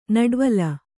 ♪ naḍvala